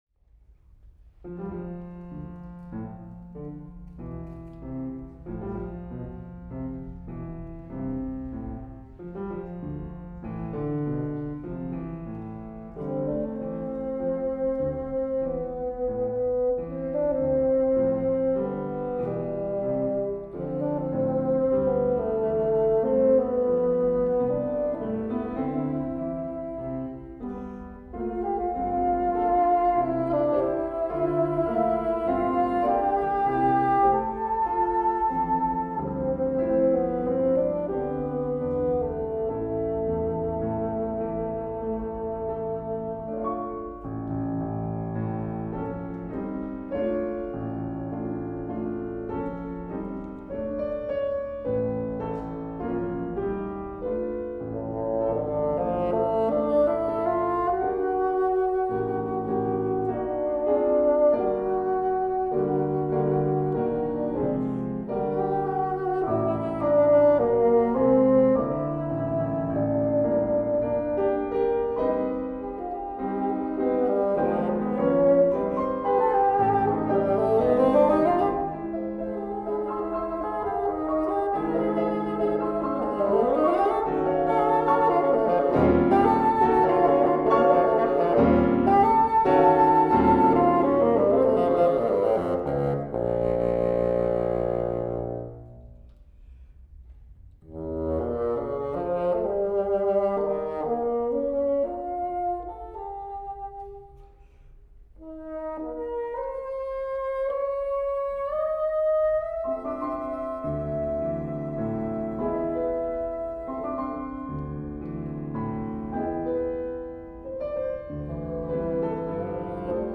DLA koncert live concert